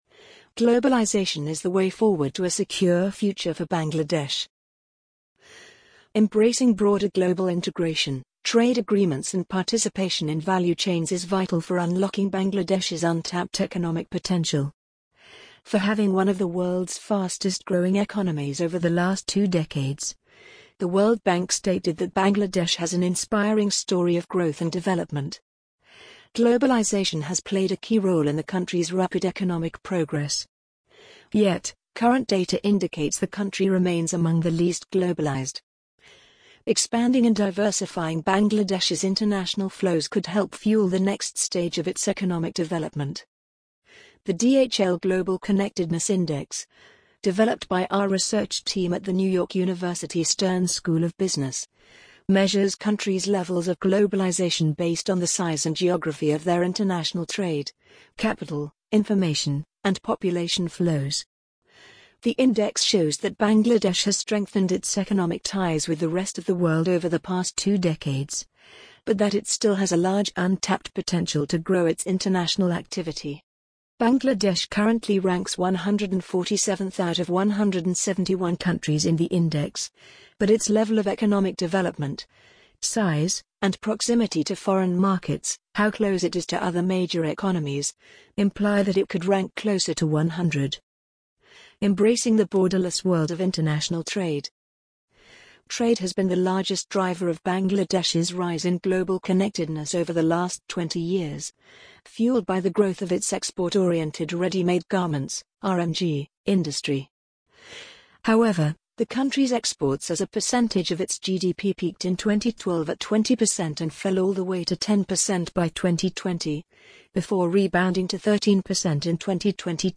amazon_polly_46134.mp3